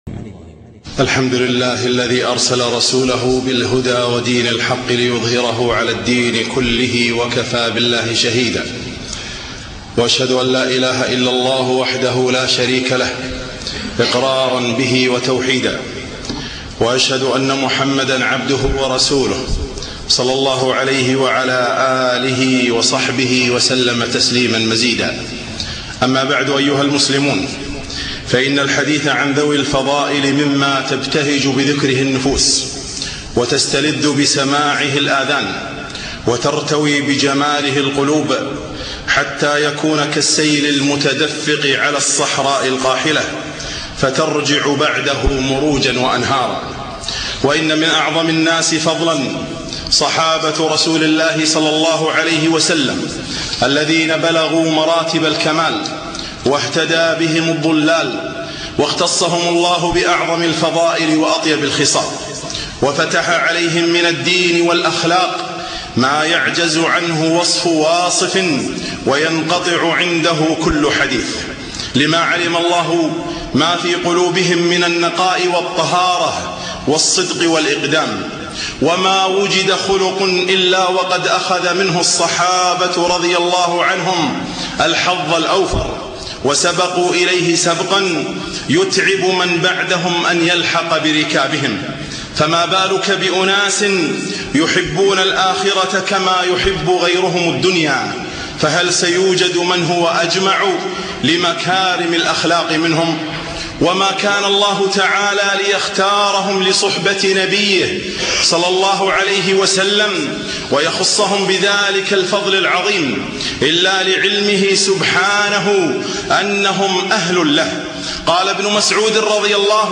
خطبة - فضل الصحابة رضي الله عنهم أجمعين